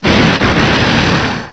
The cries from Chespin to Calyrex are now inserted as compressed cries
urshifu_rapid_strike_style.aif